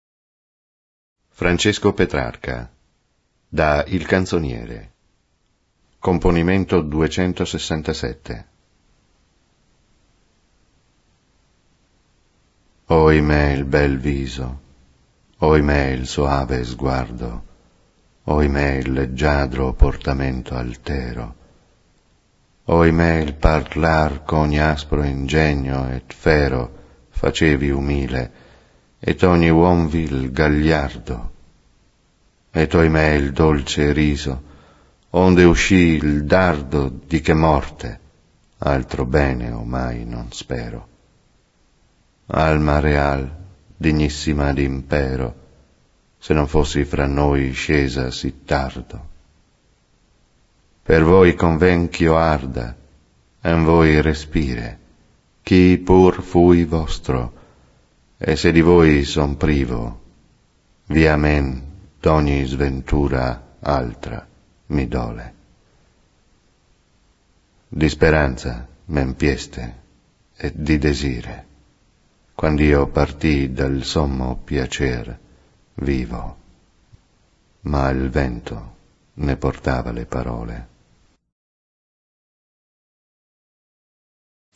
mp3) recited in Italian